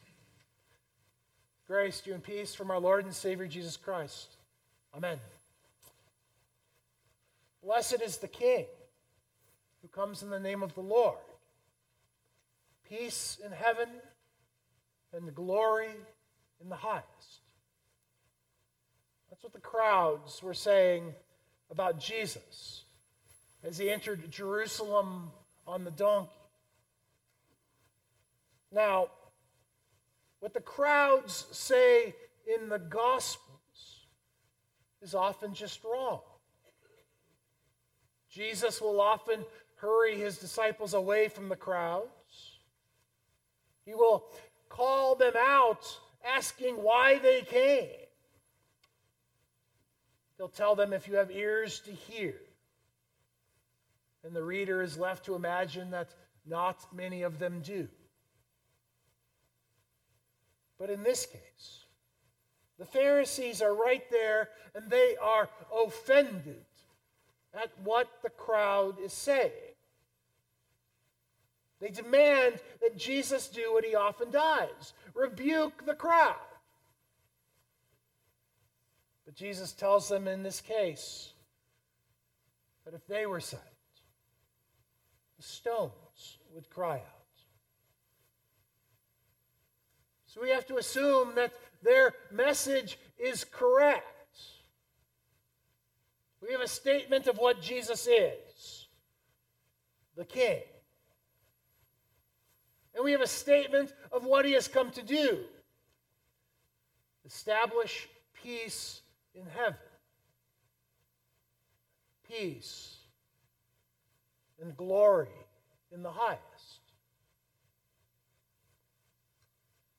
The theme or motif of the day is the Advent or Coming of the King. What this sermon takes up is our distance from even the idea of a King.